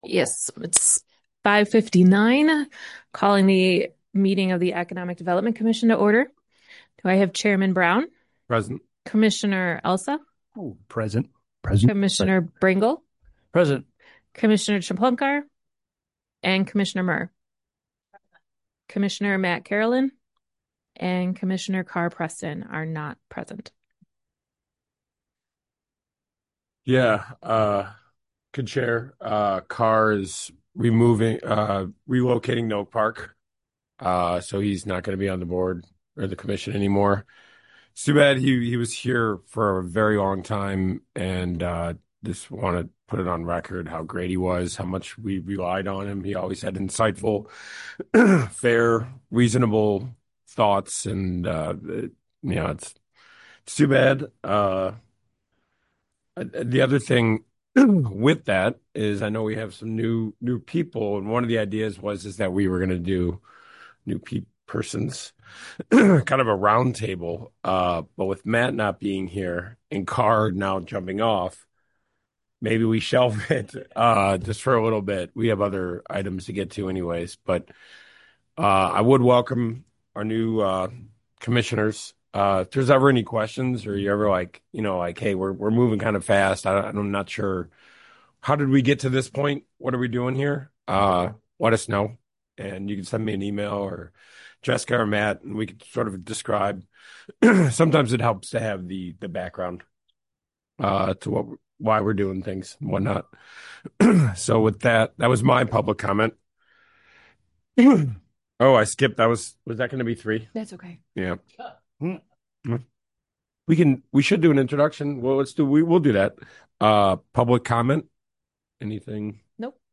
Economic Development Commission Meeting - 2nd Floor Conference Room